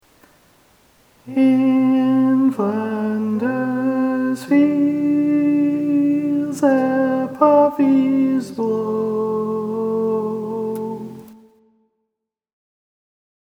Key written in: F Major
Nice gentle 4-part suspension-chord tag